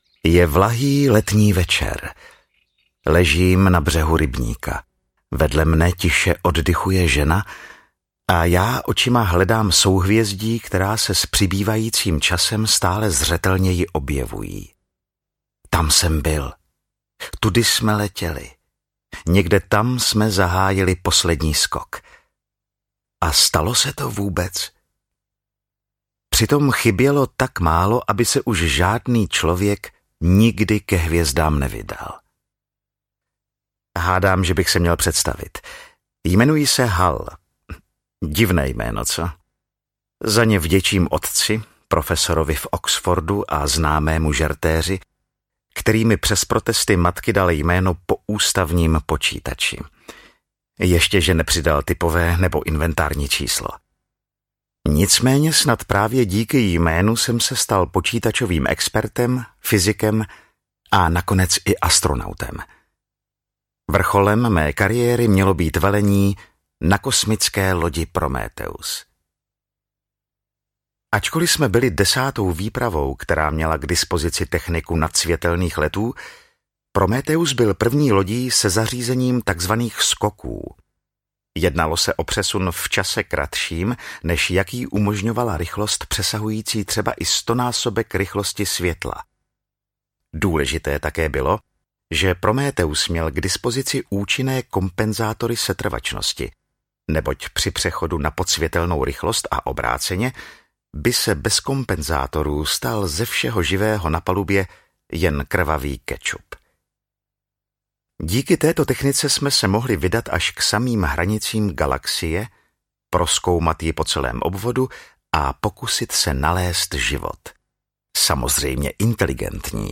Vzpoura mozků audiokniha
Ukázka z knihy
Po více než čtyřiceti letech vyšel tento komiks v románové podobě, následně vznikla dramatizace v Českém rozhlase a nyní vychází toto kultovní dílo jako audiokniha v interpretaci Aleše Procházky.
• InterpretAleš Procházka